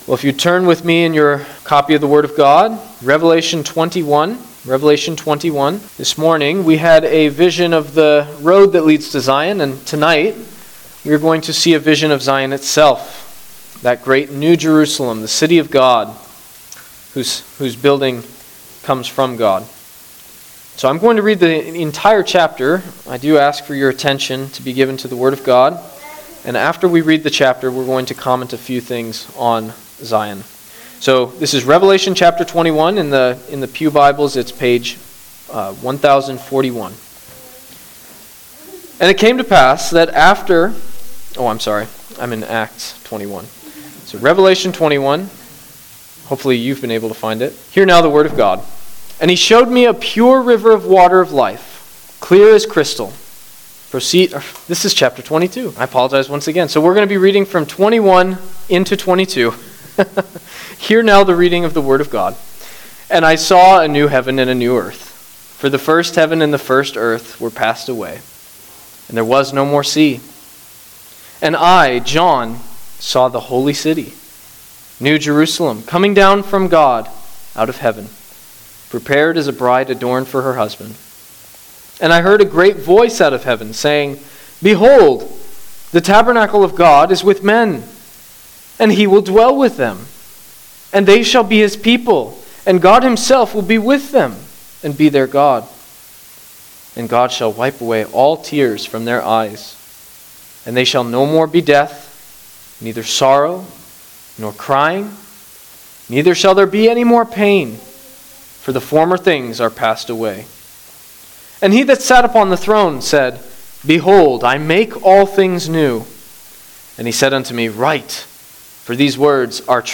Guest Preachers Passage: Revelation 21:1 – 22:5 Service Type: Sunday Evening Service Download the order of worship here .